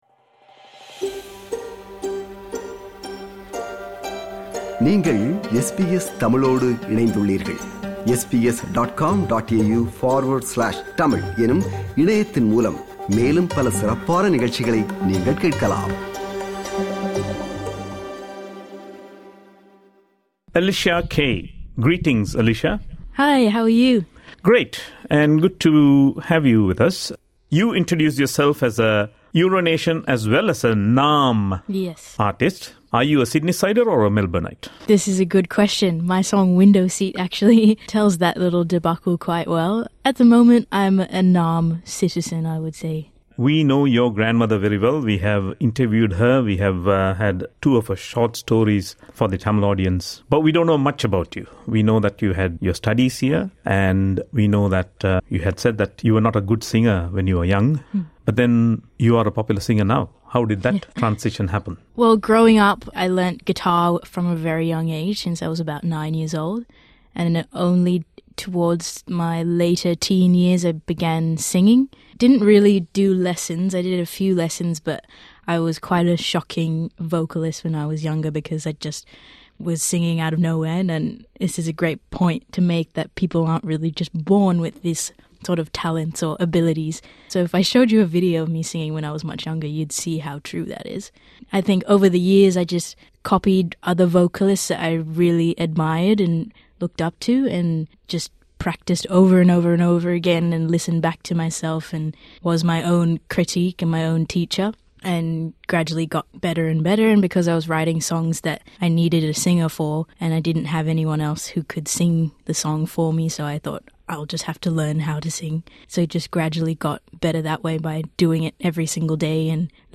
a Singer, Songwriter, and Musician SBS Tamil
In an engaging interview
a live rendition of a song she has both penned and composed